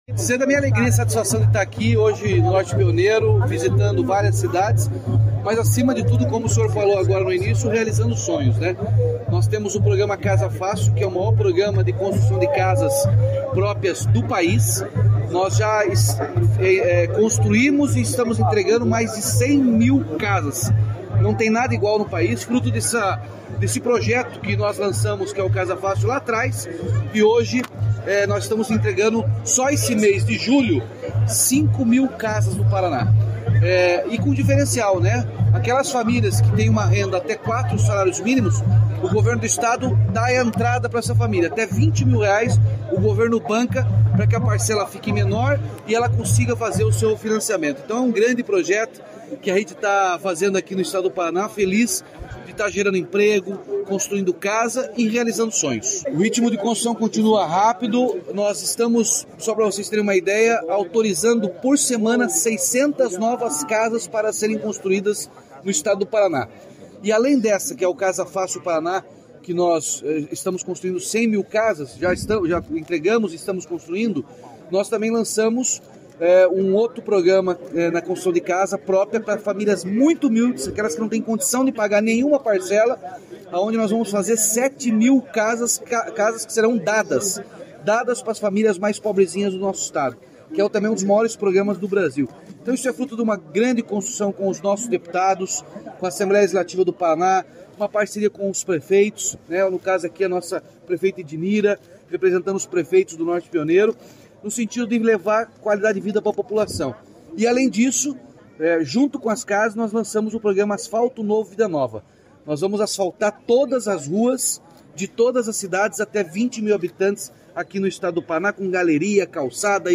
Sonora do governador Ratinho Junior sobre a entrega de residencial com 134 casas em Andirá